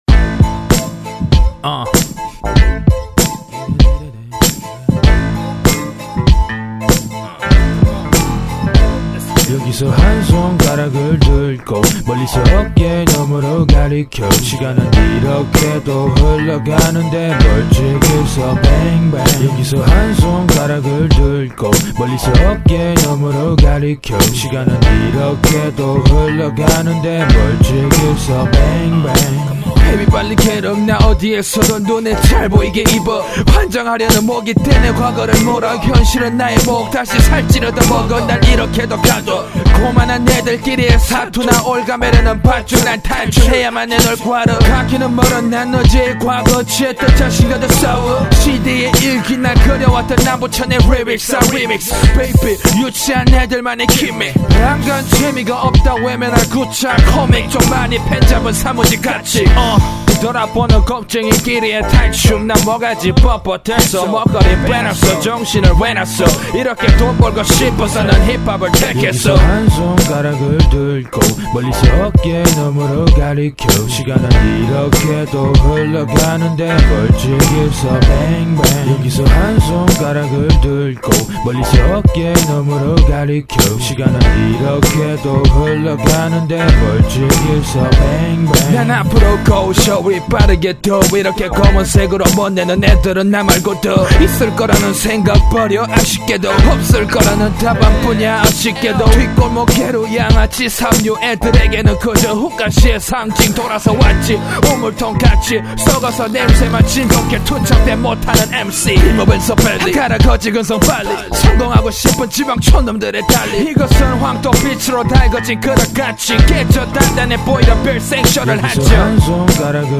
REMIX.